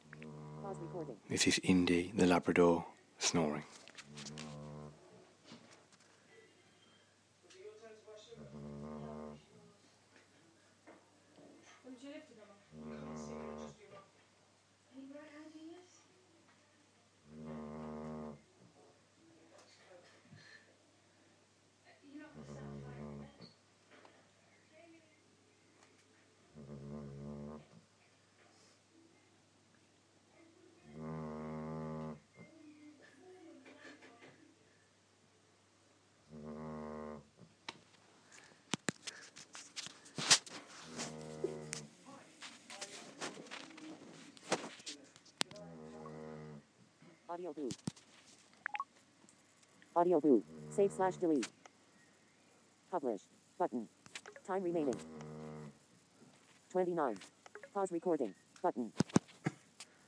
dog snore